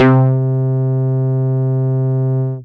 MUTE BRASS 2.wav